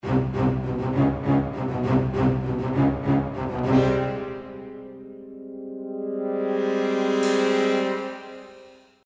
Le problème subsiste pour les samples de SforzandoCrescendo (accent suivi d’un relâchement immédiat, puis d’un crescendo).
Voici un exemple de Sforzando-Crescendo de cors français issu de la banque East-West Symphonic Orchestra Gold.
– En jaune les staccatos de basses
– En vert le fameux Sforzando-Crescendo de cors français
– En bleu, un petit coup de cymbale qui me sert de repère